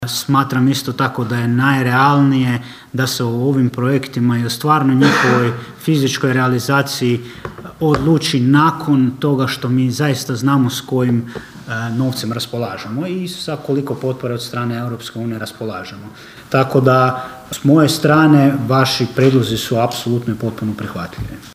Predsjednik Općinskog vijeća Ivan Zambon kazao je kako se slaže s prijedlozima: (